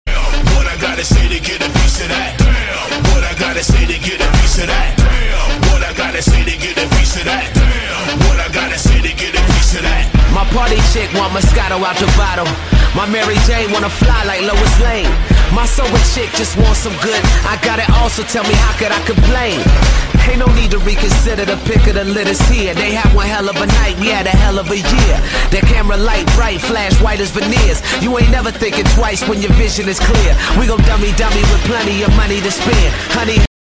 • Качество: 128, Stereo
OST